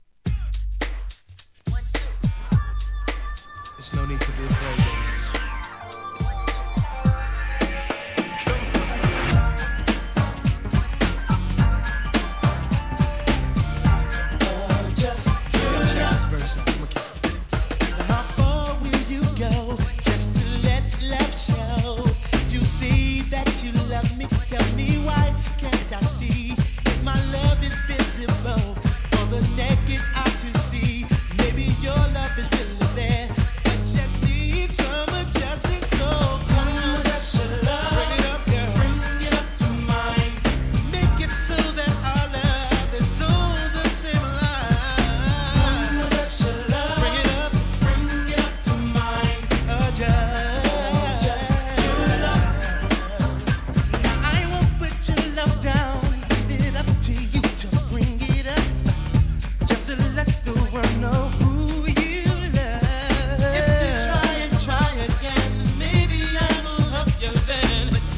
1. HIP HOP/R&B